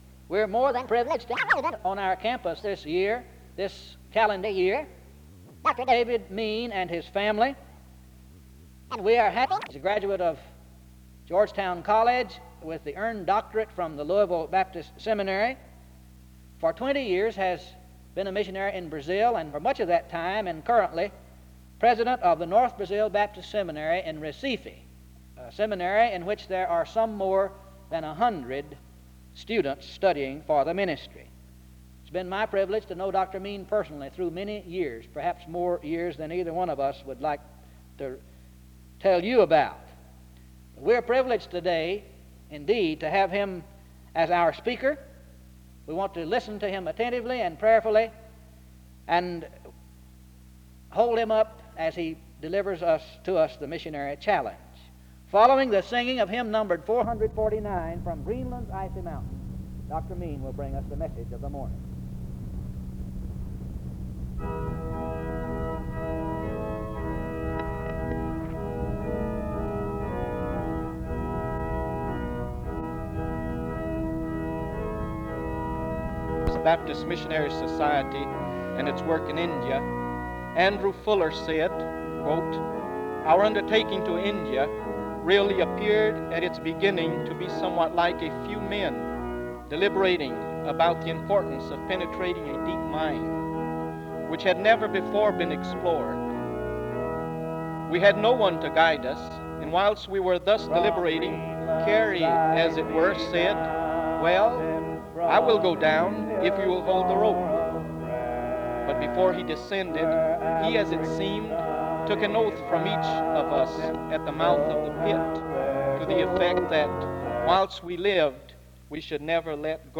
The service begins with an introduction to the speaker from 0:00-1:02.
A closing hymn is sung from 23:37-25:33. The service closes with prayer from 25:34-25:54.